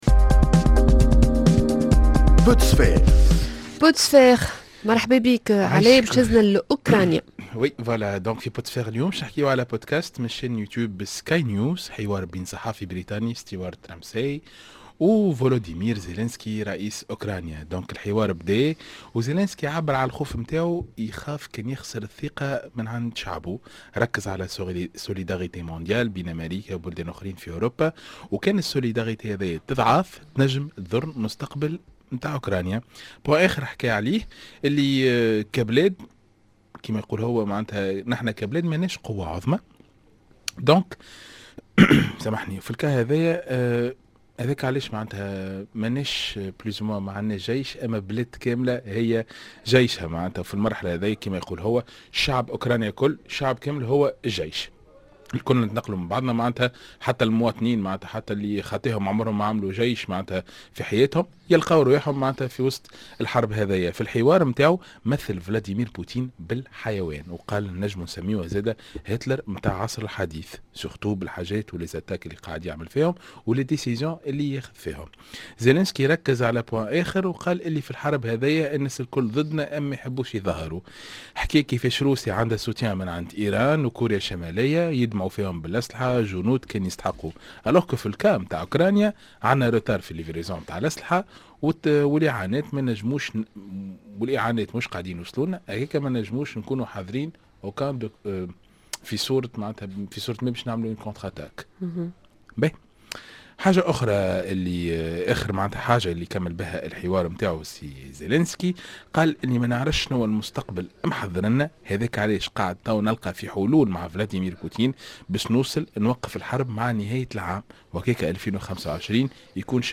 L'interview inédite de Volodymyr Zelensky !